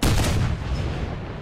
fireenemy2.ogg